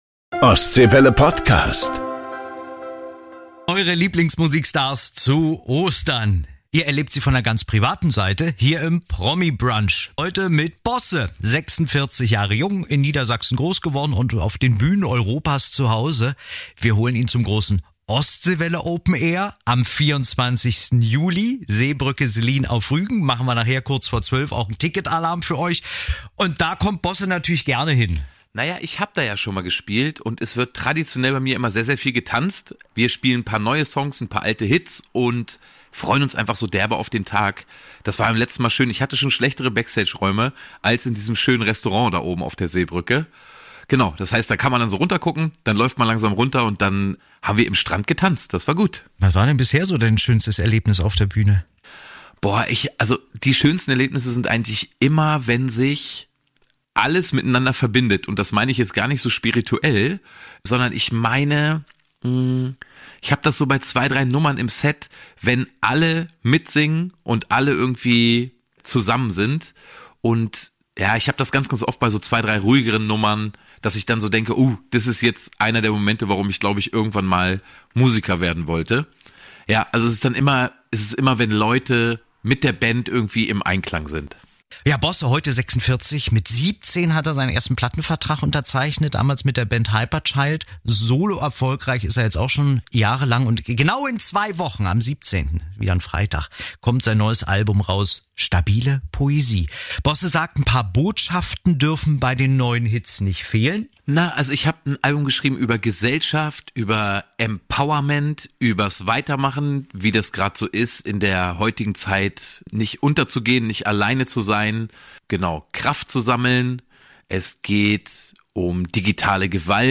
Eure Lieblingsstars zu Gast bei Ostseewelle